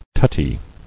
[tut·ty]